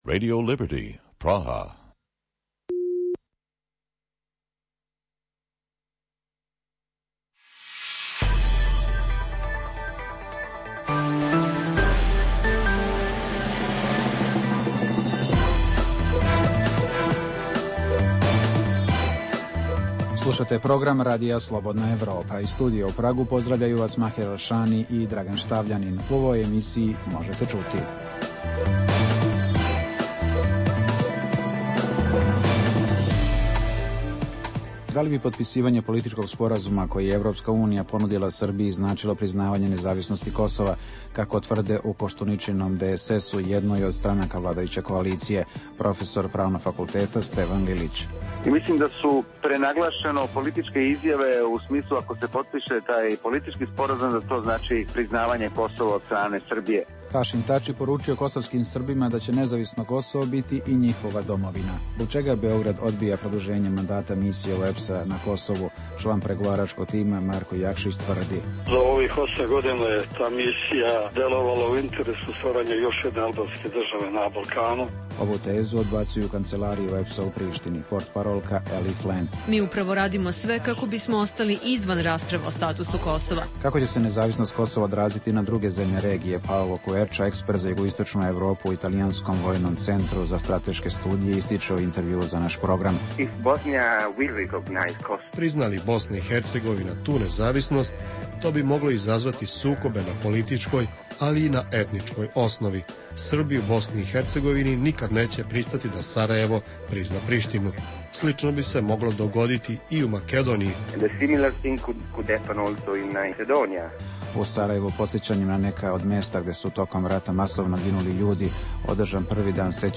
Sve veći broj građana Hrvatske podržava članstvo u NATO-u. Intervjui sa ekspertima iz Rima i Londona